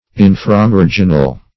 Search Result for " inframarginal" : The Collaborative International Dictionary of English v.0.48: Inframarginal \In`fra*mar"gin*al\, a. [Infra + marginal.] Below the margin; submarginal; as, an inframarginal convolution of the brain.